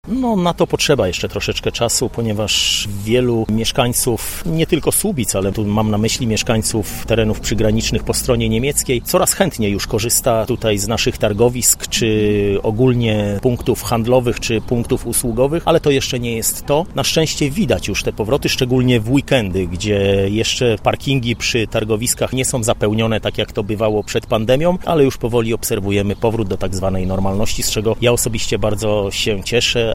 Dziś sytuacja normuje się, o czym mówi burmistrz Słubic, Mariusz Olejniczak: